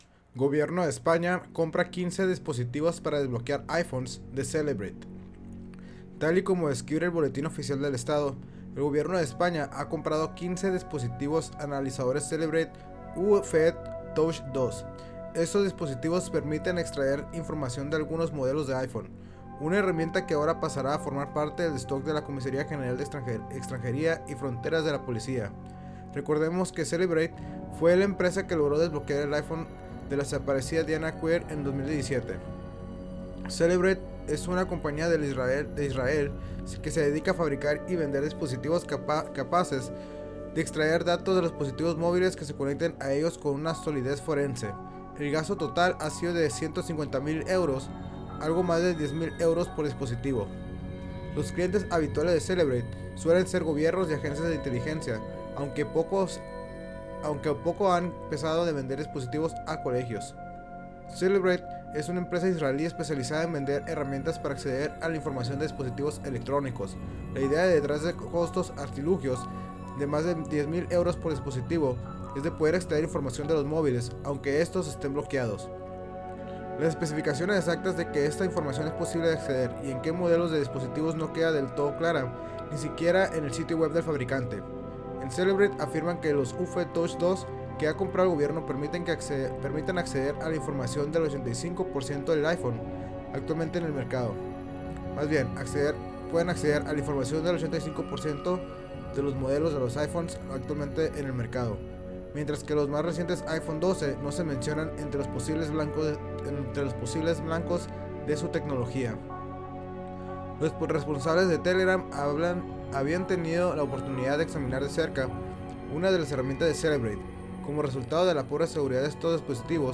Créditos Música :